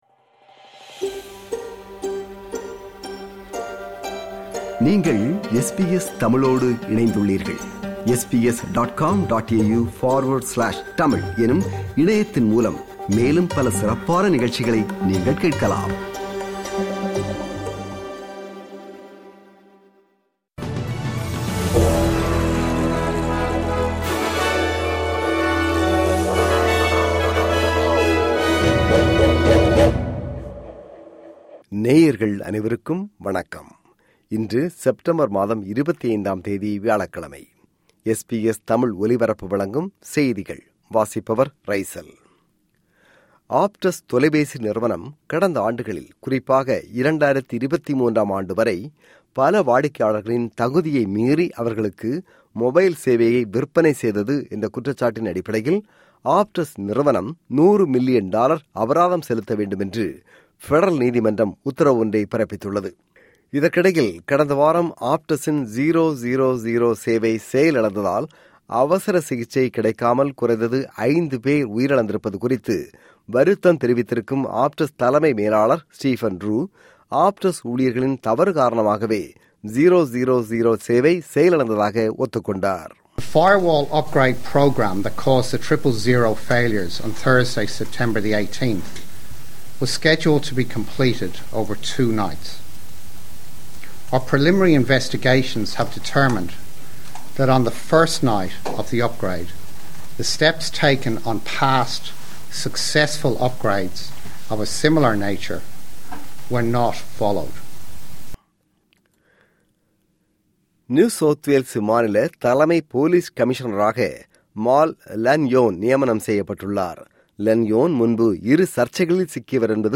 இன்றைய செய்திகள்: 25 செப்டம்பர் 2025 வியாழக்கிழமை
SBS தமிழ் ஒலிபரப்பின் இன்றைய (வியாழக்கிழமை 25/09/2025) செய்திகள்.